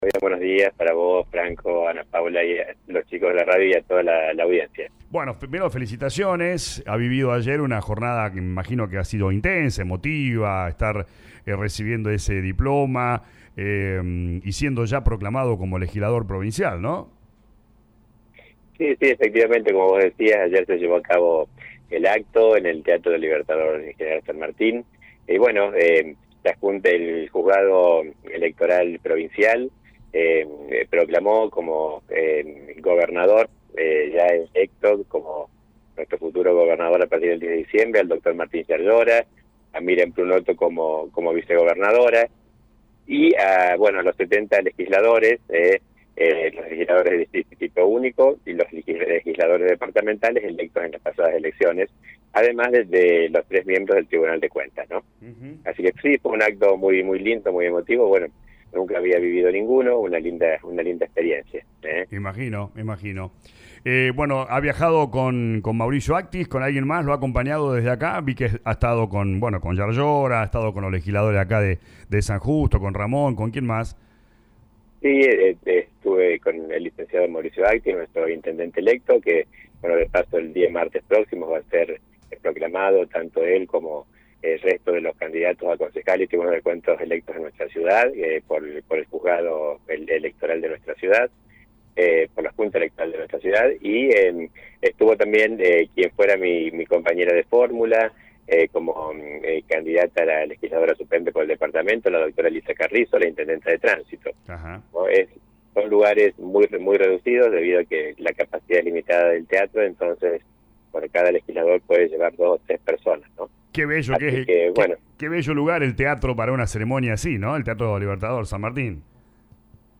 En diálogo con LA RADIO 102.9, el intendente de Brinkmann expresó que “nunca había vivido ningún acto de estas características. Fue una linda experiencia”.